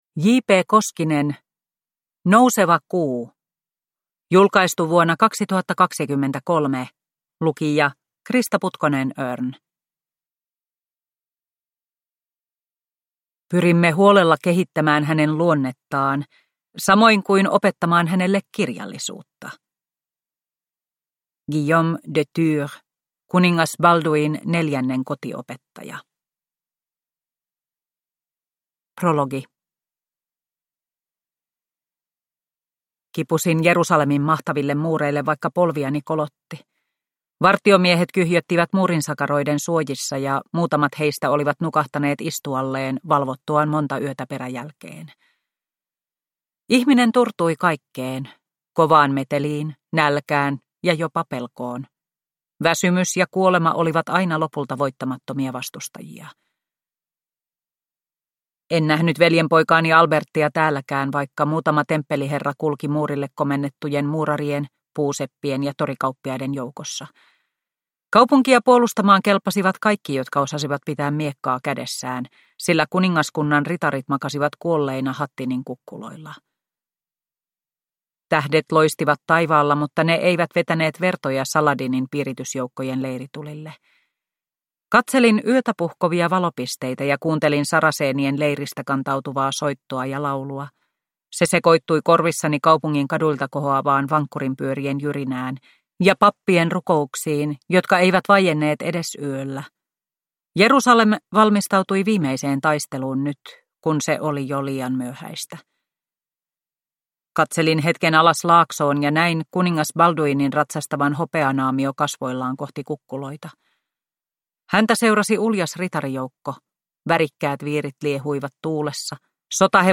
Nouseva kuu – Ljudbok – Laddas ner